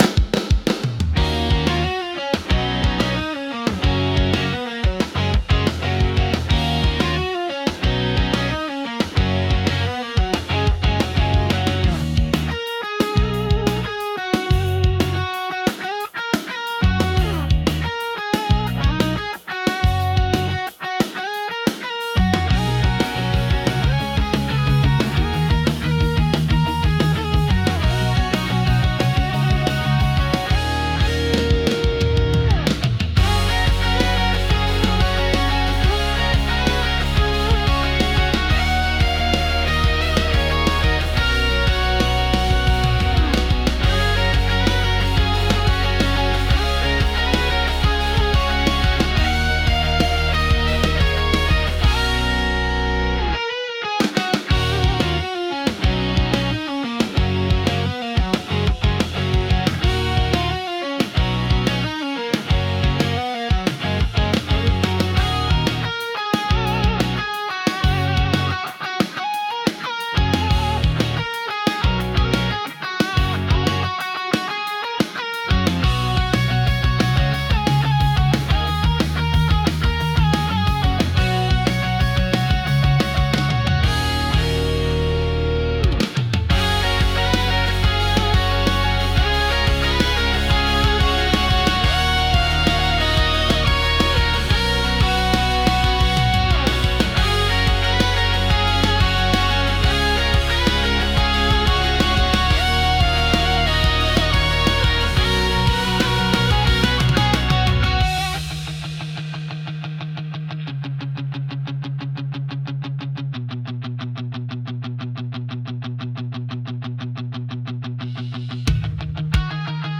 Genre: Pop Punk Mood: Emotions Editor's Choice